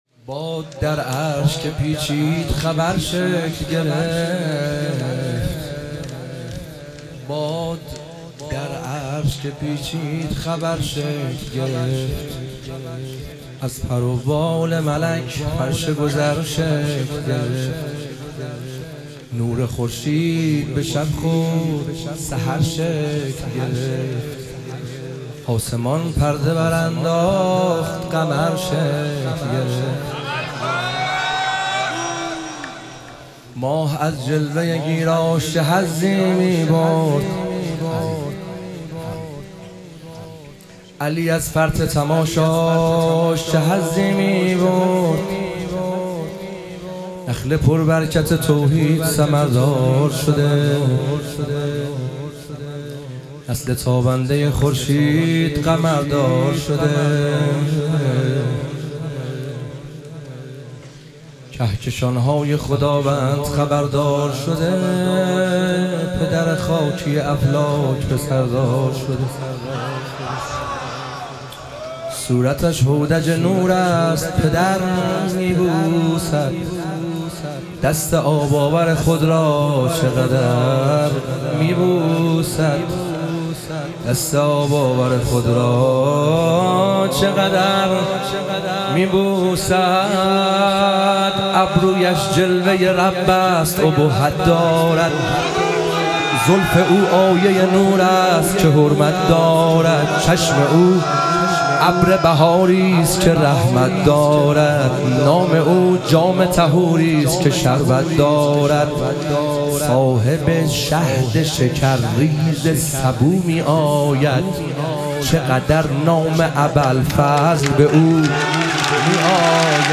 ظهور وجود مقدس حضرت عباس علیه السلام - مدح و رجز